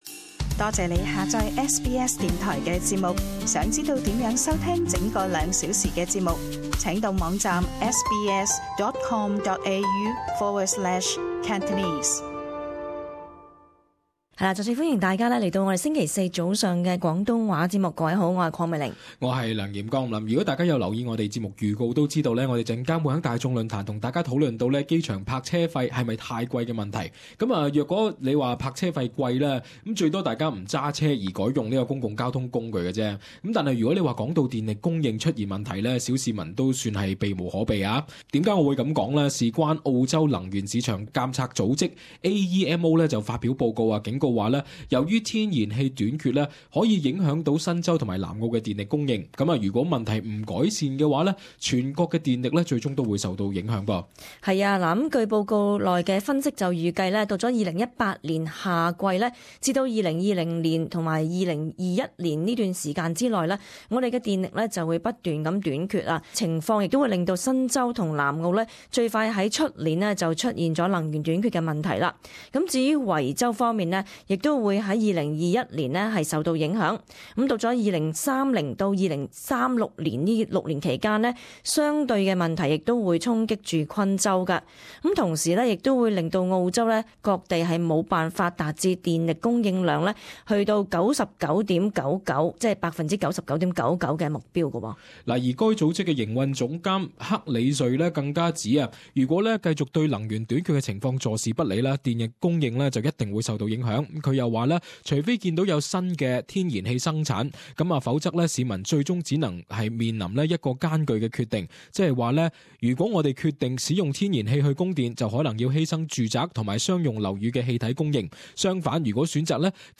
【時事報導】全國能源短缺危機最快明年湧現